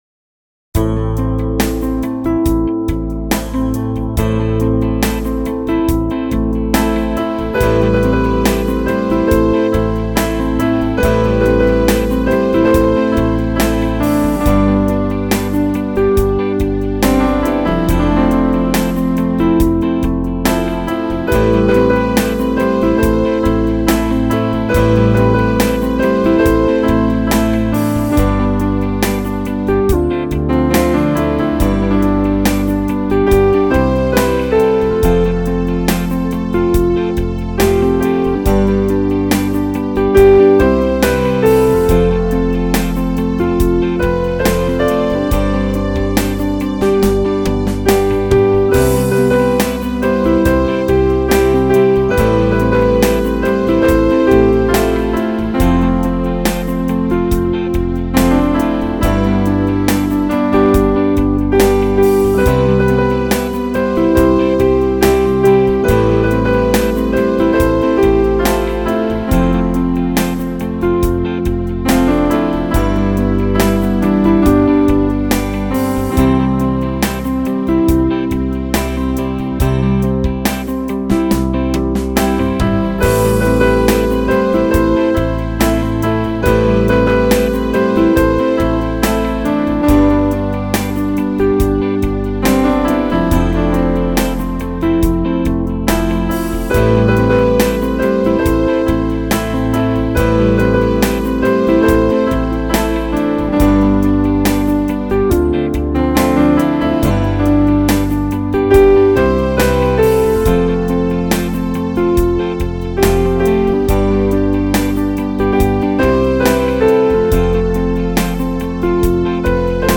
Instrumentalaufnahme